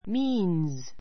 míːnz ミ ーン ズ